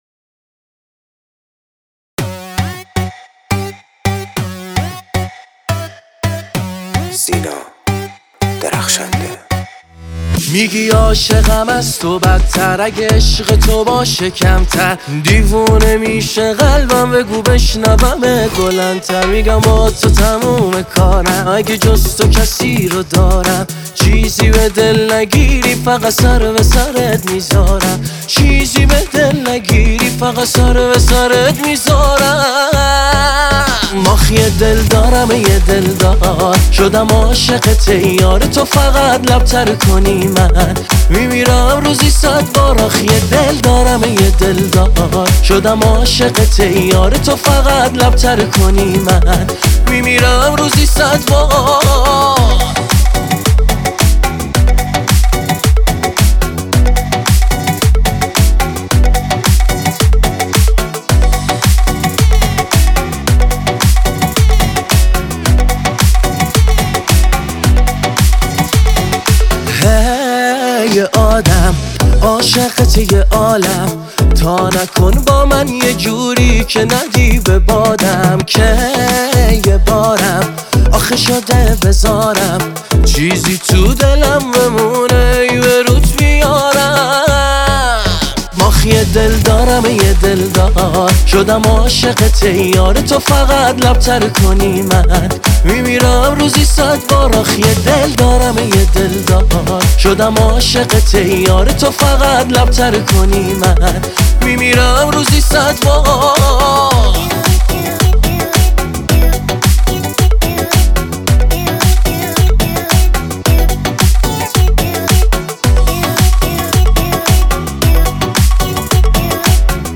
آهنگ شاد
Happy Music